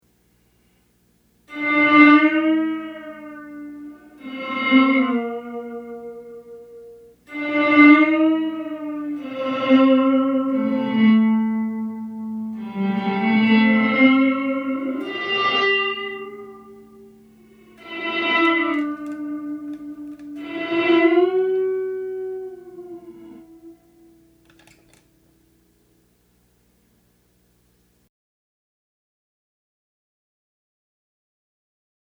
I just recorded in an open room with no filtering. BTW, I used a Strat with a prototype Aracom RoxBox 18 Watt Amp with a Jensen 1 X10 speaker.
rv7_reverse.mp3